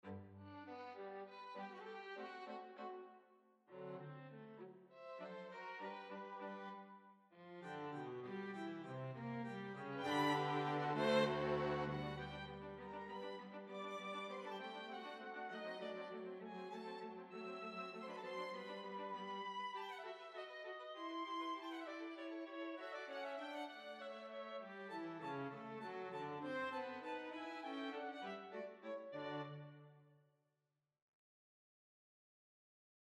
Recommendations for VST instruments for string quartet
this one: SYNCHRON-ized SOLO STRINGS - Vienna Symphonic Library
here is a few measures of Haydn using the VSL provided Dorico template: